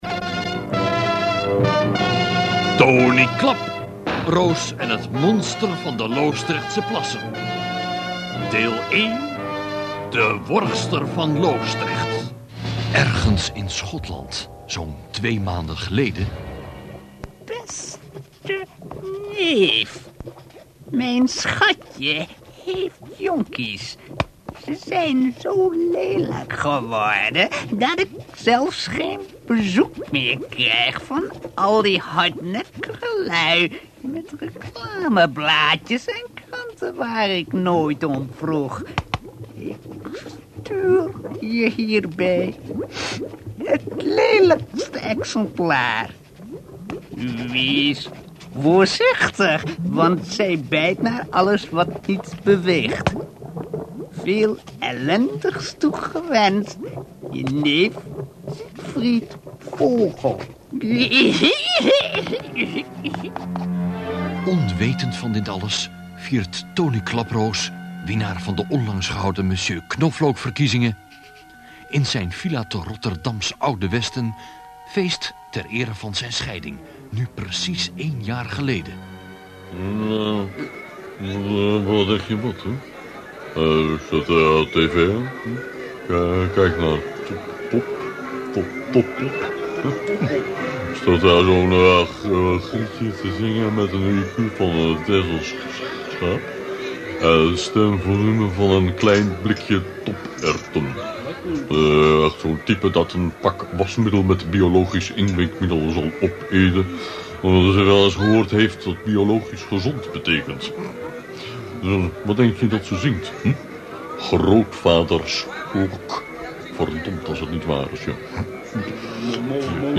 Ik vond in een oude doos een paar tapes met mono opnames van crappy kwaliteit.